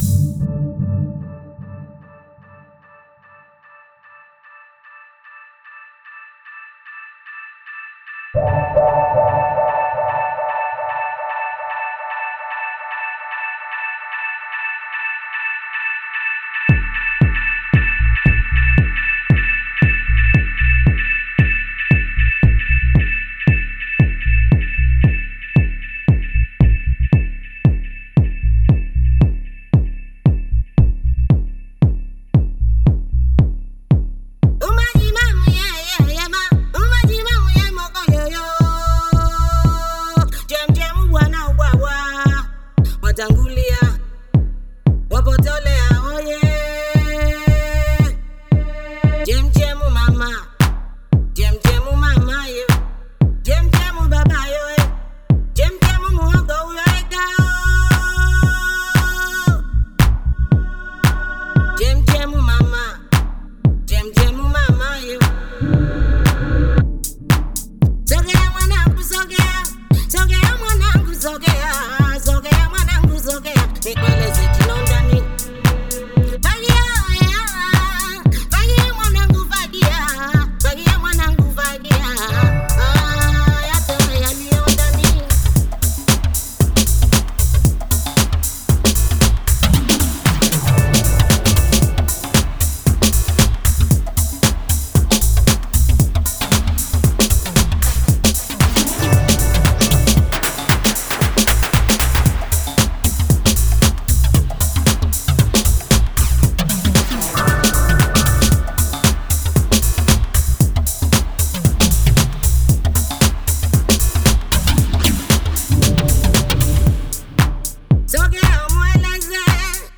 percussion
Afro disco